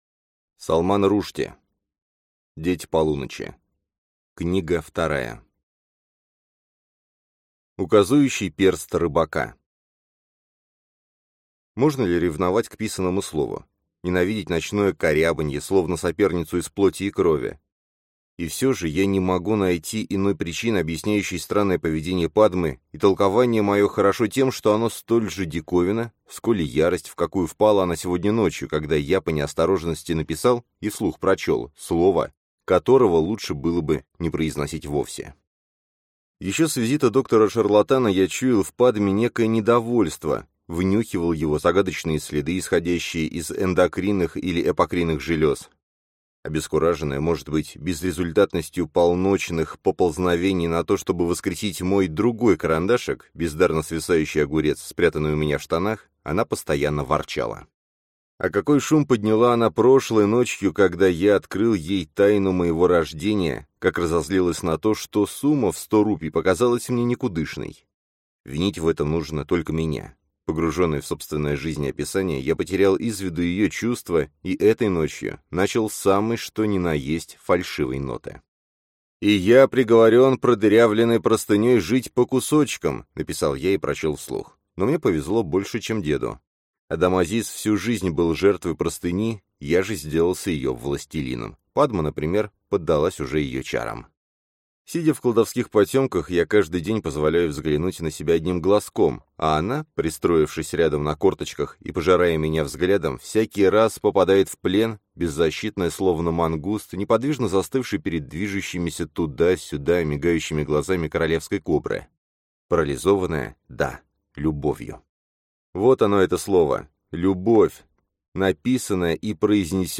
Аудиокнига Дети полуночи. Книга вторая | Библиотека аудиокниг